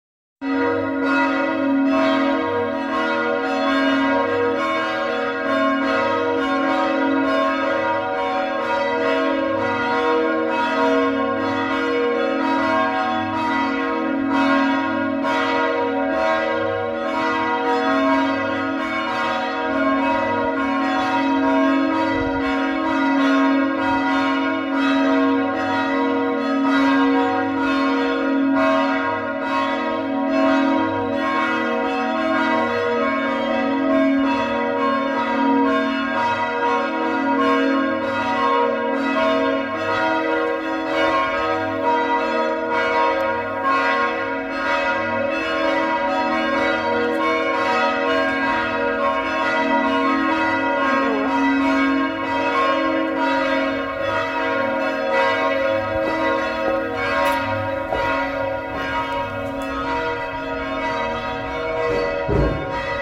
Glocken_Bieber.mp3